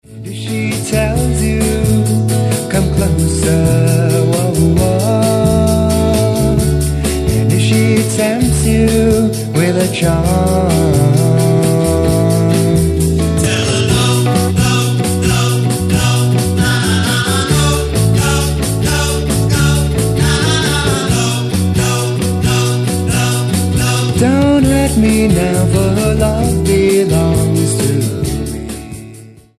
Power Pop
Voz, bajo, guitarra rítmica
Voz, batería, armónica
Guitarra acústica
Guitarra solista